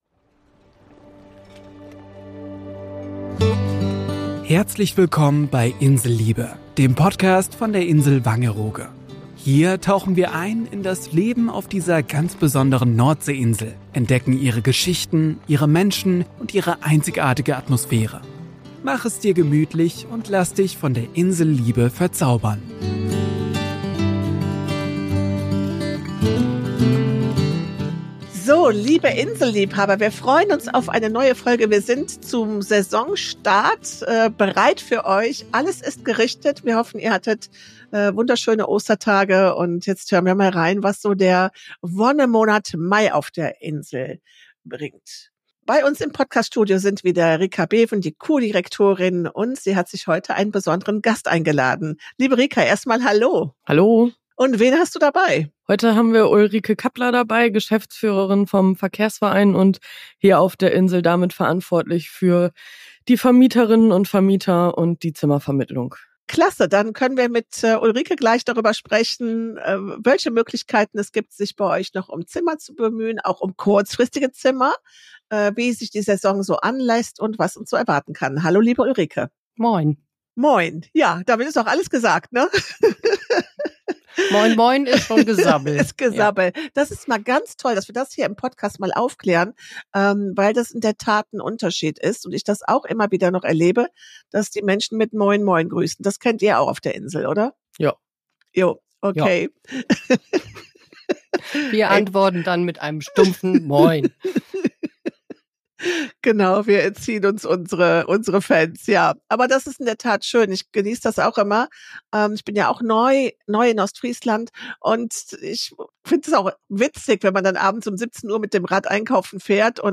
Hören Sie gerne rein bei dem Talk mit Meeresrauschen!